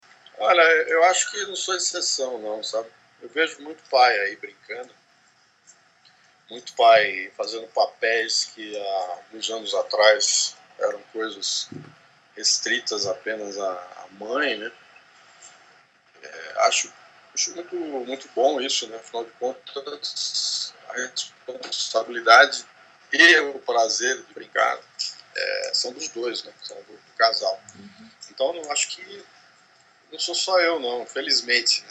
Escute a opinião do publicitário sobre a participação dos pais atualmente na dinâmica familiar.